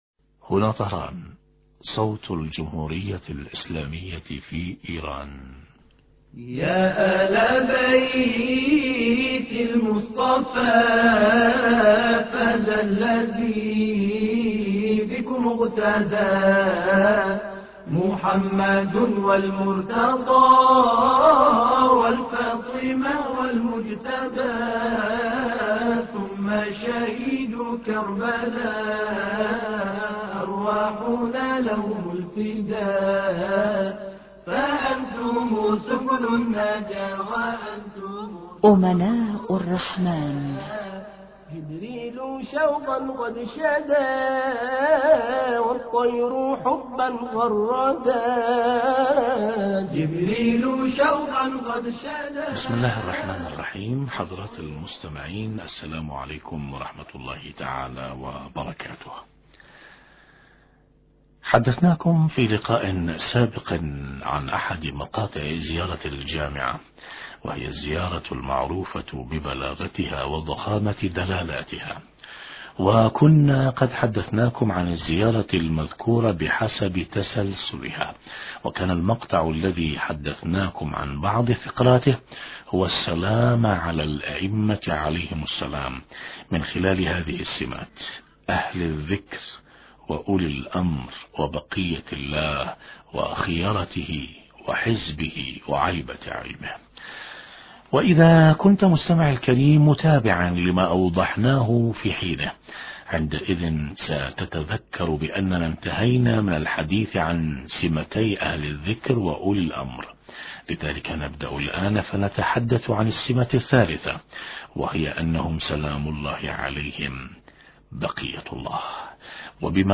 حديث عن معنى بقية الله وأن المقصود حوار مع خبير البرنامج عن التكامل في أدوار الائمة(ع) في حفظ الدين شرح فقرة: بقية الله من أوصاف الائمة(ع)